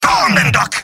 Robot-filtered lines from MvM.